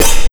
JMP130CYMB-L.wav